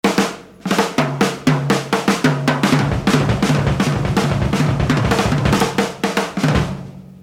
outro solo